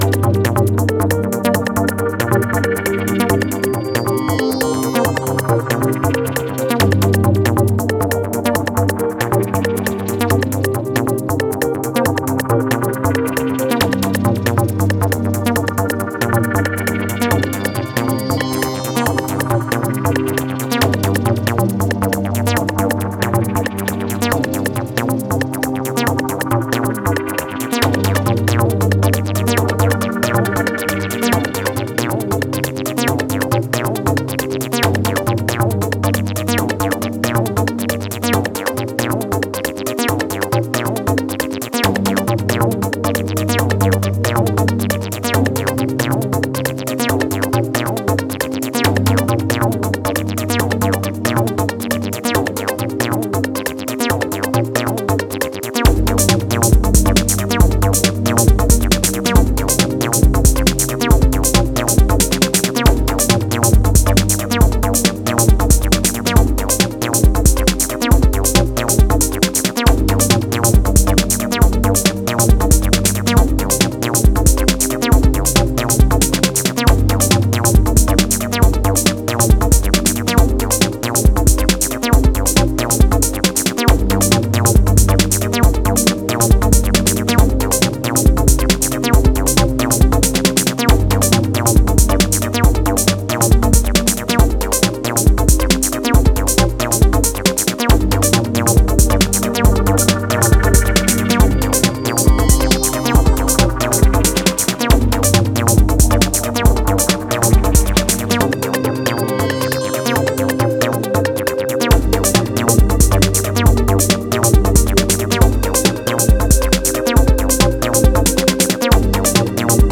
focuses on an IDM sound
a conceptual acid and Deep style